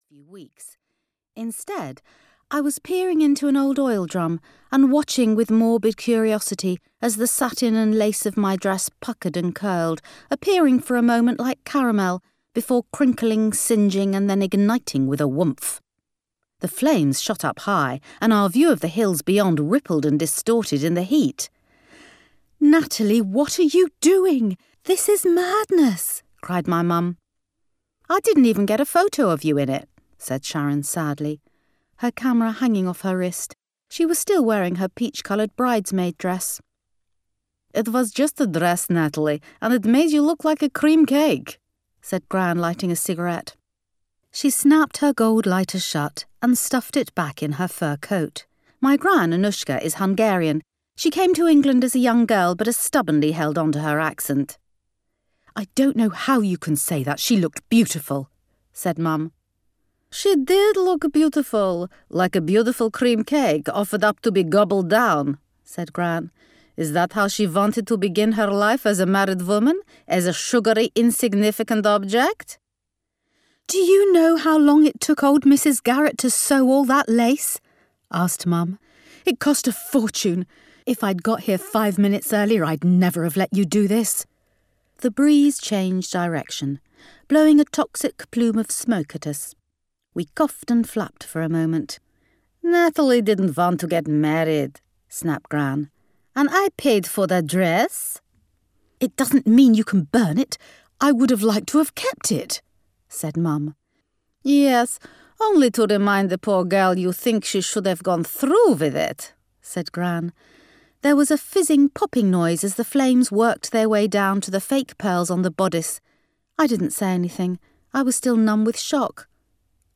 Miss Wrong and Mr Right audiokniha
Ukázka z knihy